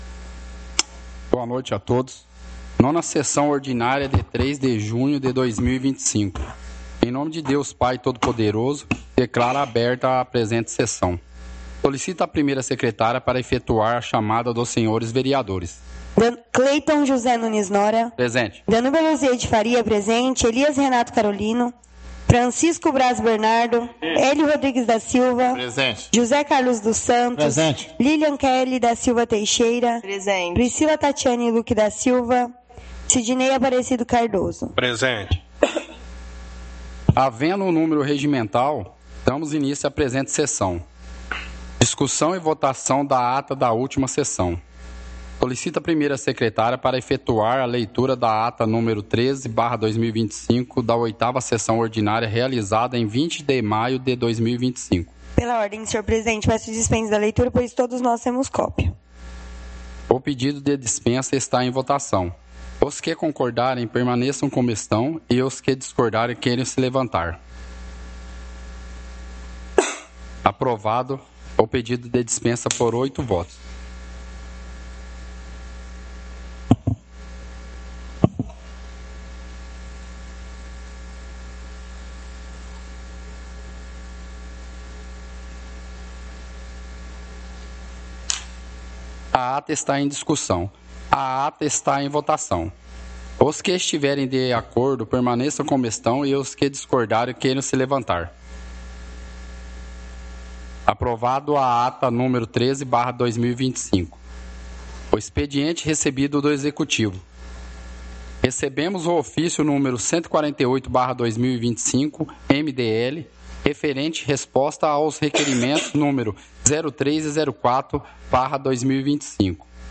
Áudio da 9ª Sessão Ordinária – 03/06/2025